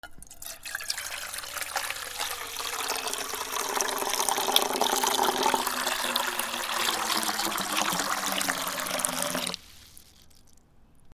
/ M｜他分類 / L30 ｜水音-その他
水を容器に注ぐ
『ゴポゴポ』